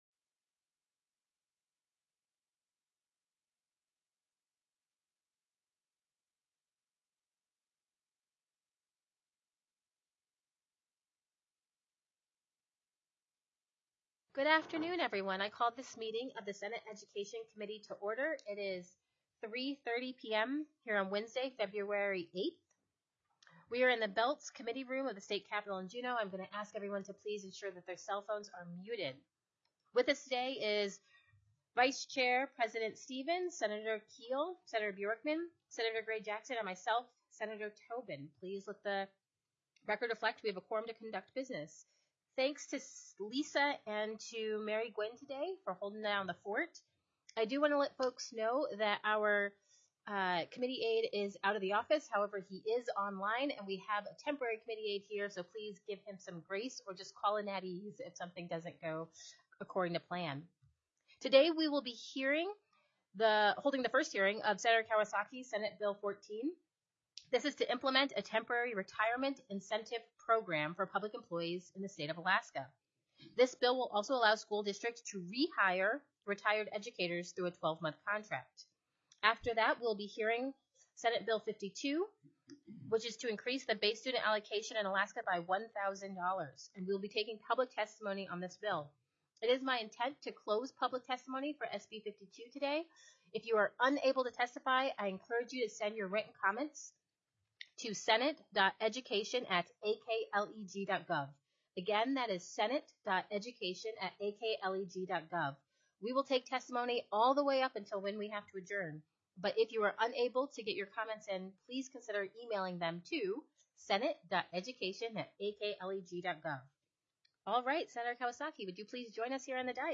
02/08/2023 03:30 PM Senate EDUCATION
The audio recordings are captured by our records offices as the official record of the meeting and will have more accurate timestamps.